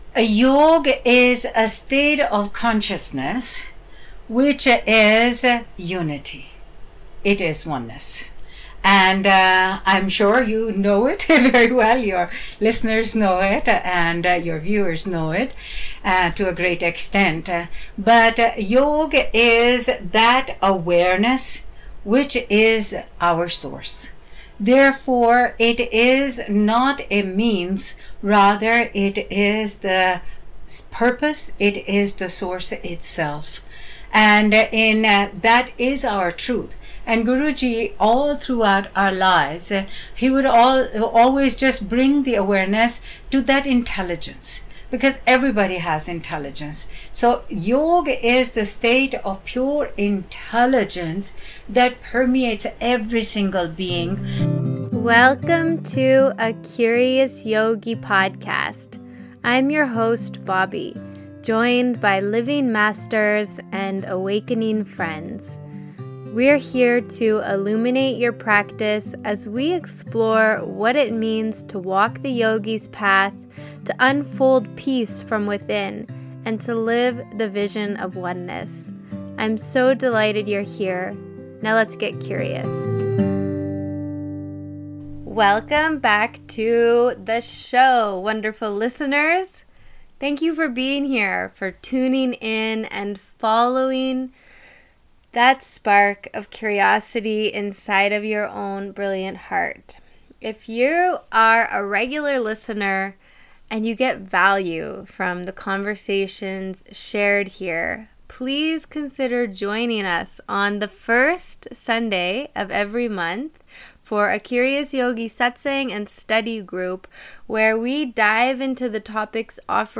Join me weekly for joyful conversations with wise teachers, sincere yogis and other spiritual seekers. We inquire into our deepest questions and learn how to apply the ancient wisdom into our own daily practice…and life.